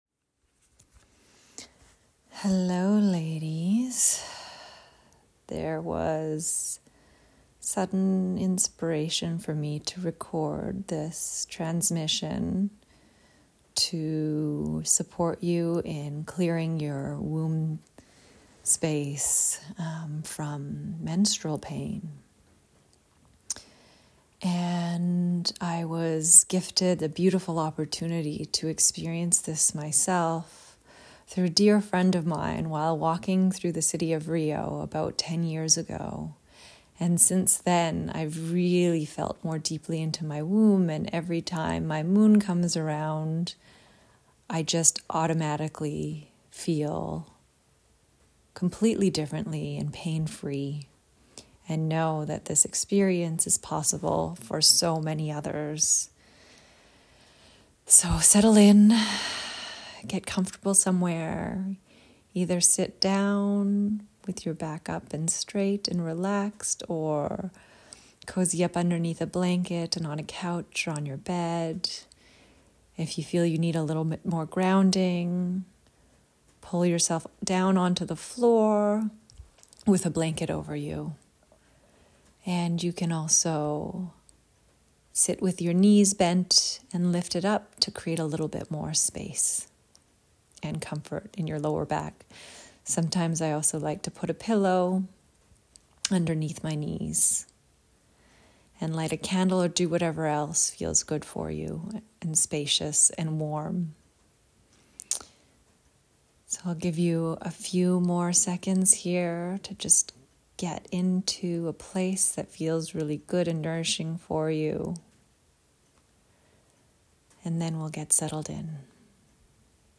Meditation: Clearing Your Womb Space of Mentrual Pain